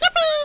YIPPEE.mp3